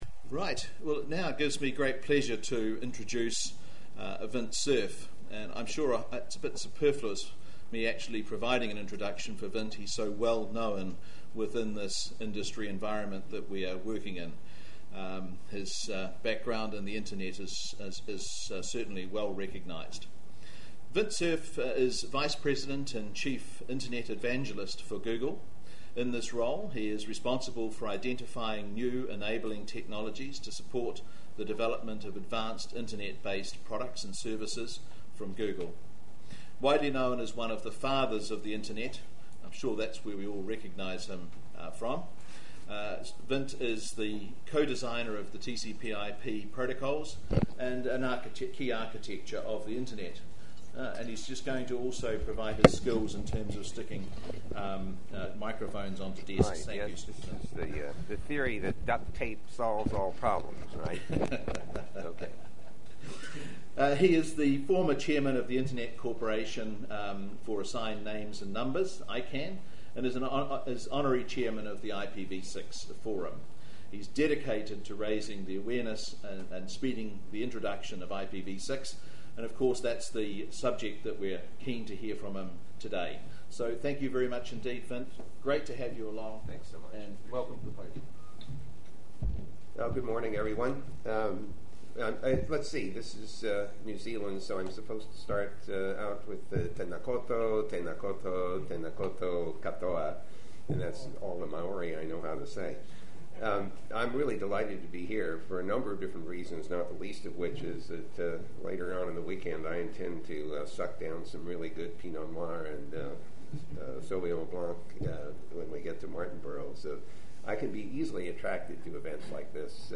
Dr Vint Cerf Keynote Speech